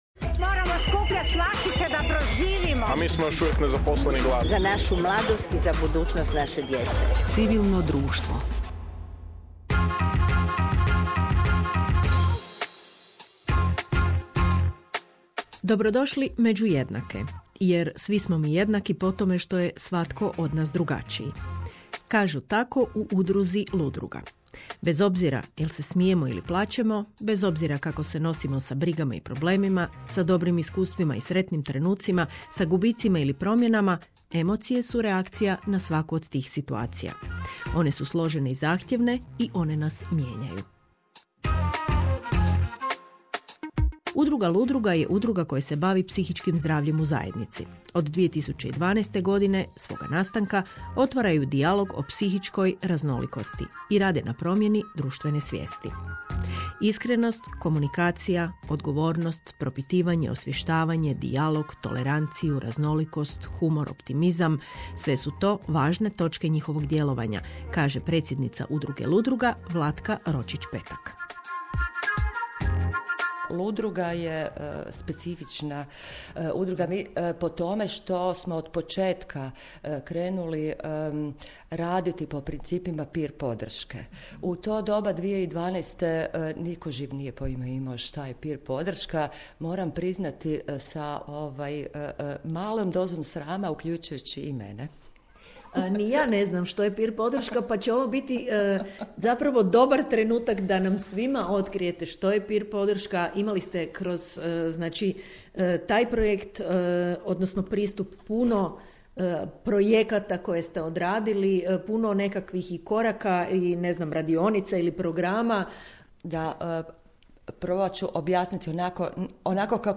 S veseljem dijelimo gostovanje naše udruge Ludruga u emisiji Civilno društvo na Hrvatskom radiju. U razgovoru smo predstavili naš rad, vrijednosti koje nas vode te aktivnosti kojima nastojimo doprinijeti zajednici, s posebnim naglaskom na važnost peer podrške i izvaninstitucionalnih oblika skrbi.